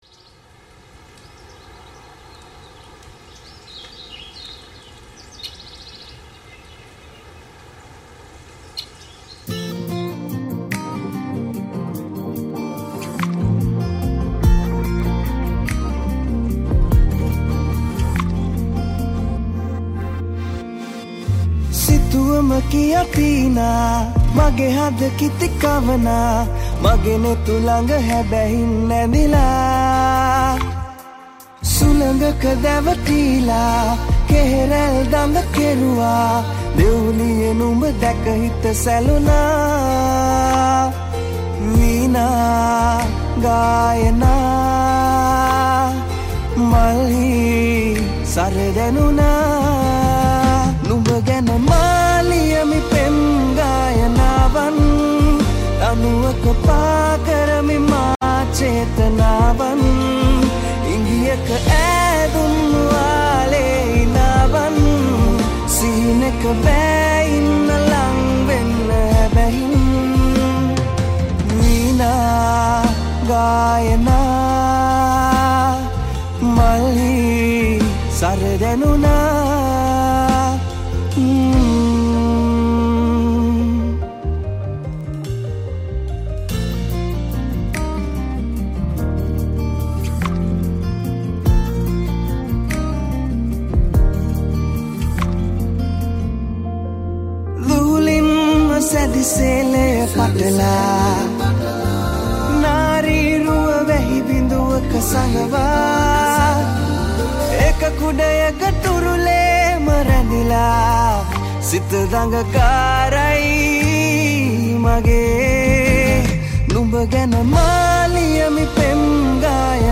Keys
Guitars